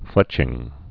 (flĕchĭng)